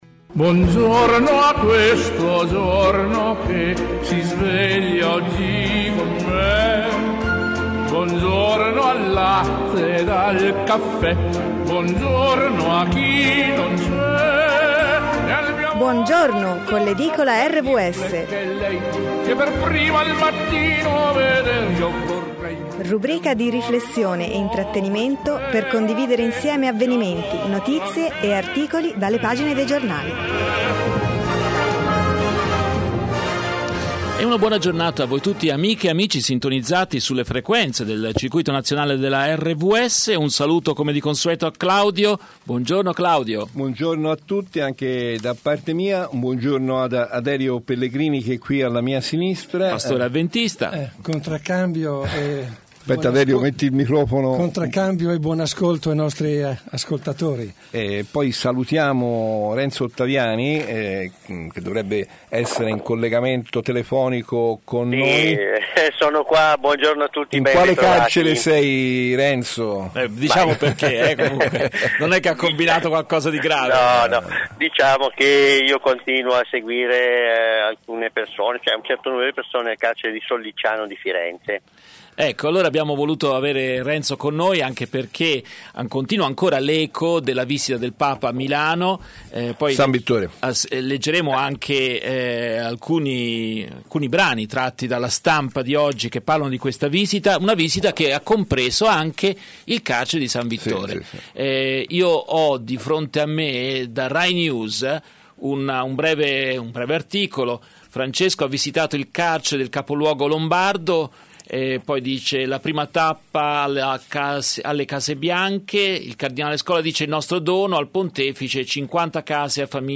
Lunedì 27 marzo la nostra Edicola si apre con la lettura dei titoli dalla prima pagina de La Stampa di Torino, per poi come sempre soffermarci su alcune notizie e articoli